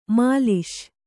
♪ māliś